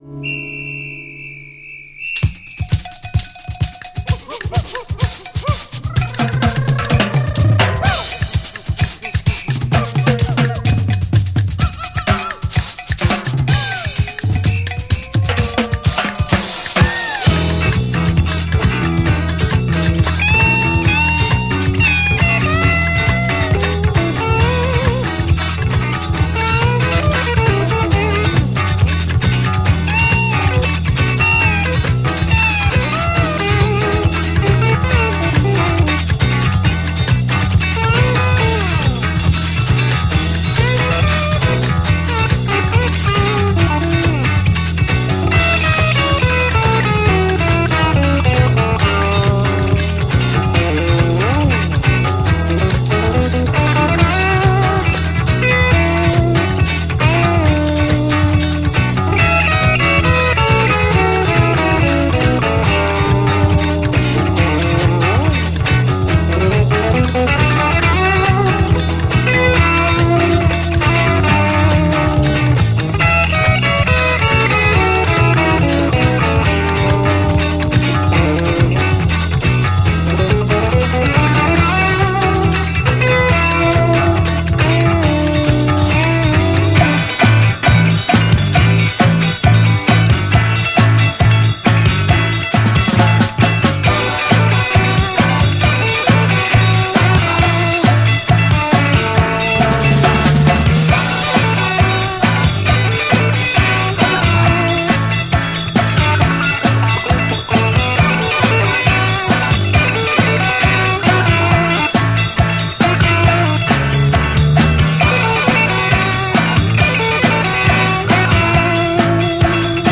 Free Soul, Disco sweden
スウェーデンの5人組プログレ・バンド